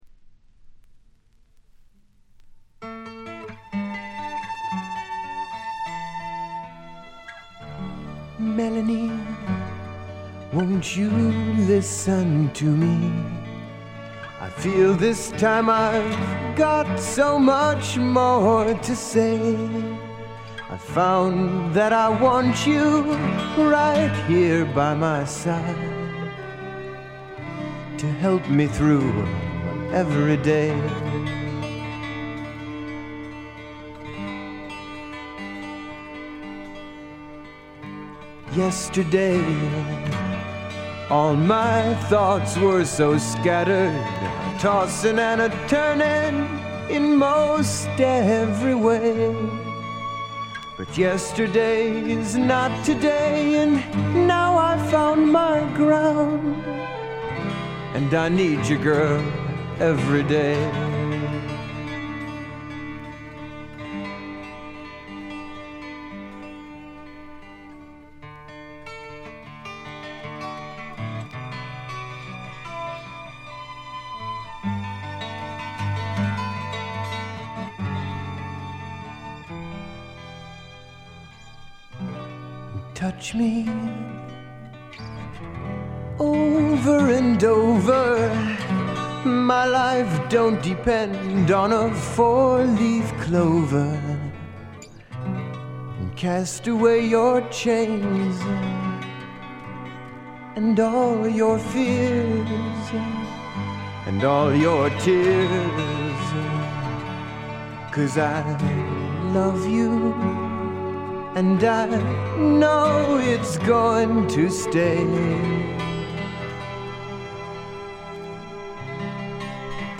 いうまでもなく米国産アシッド・フォークの超有名レア盤にして永遠の至宝です。
異常に美しいアコースティック・ギターの響きとスペイシーなシンセが共鳴する異空間。
試聴曲は現品からの取り込み音源です。